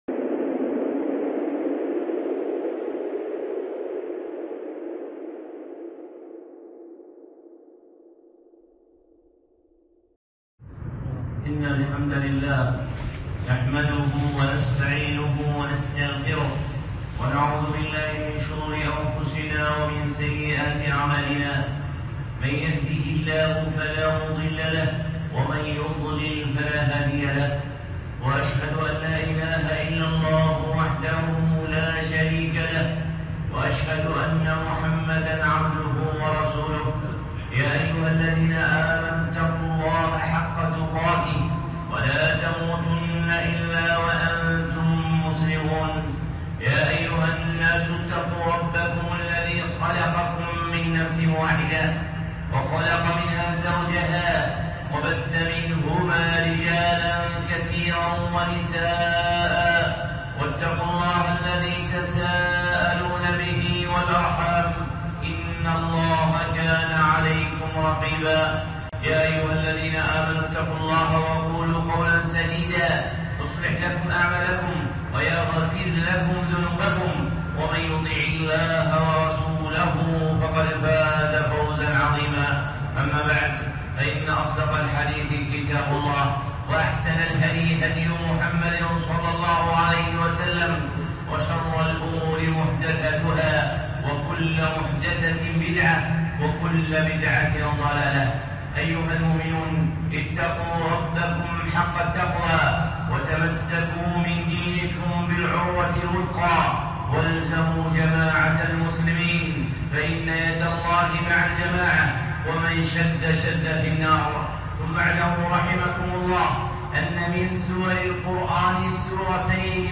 خطبة (مفتاح يومنا من القرآن)